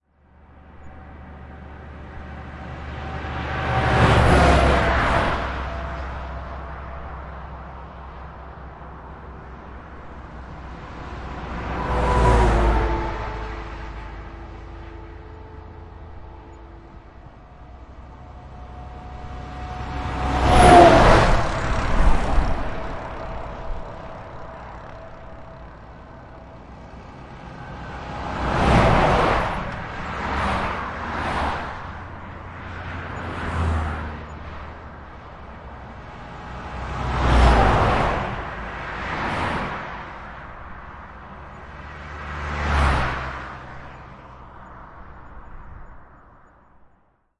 在这里，在乡村公路上，卡车和汽车通过自然多普勒效应。由我自己用Zoom H4录制。
Tag: 道路 环境 汽车 交通 汽车 卡车 现场录音 噪音 大气